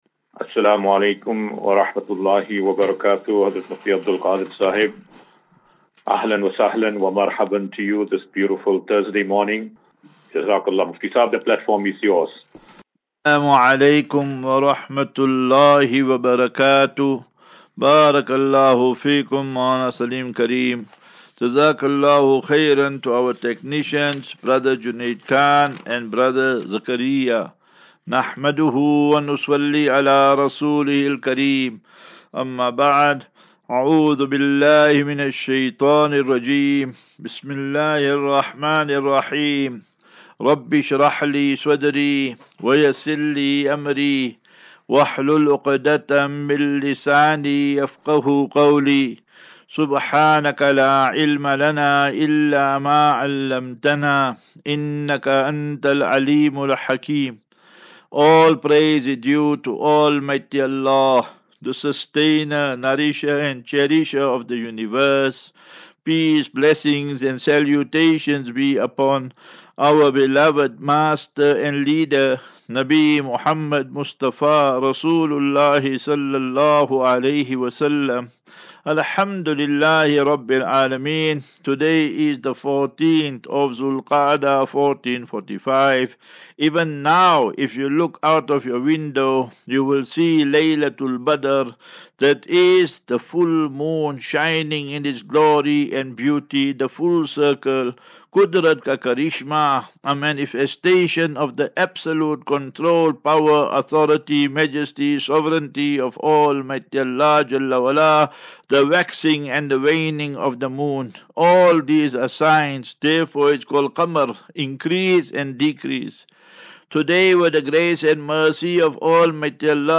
As Safinatu Ilal Jannah Naseeha and Q and A 23 May 23 May 2024.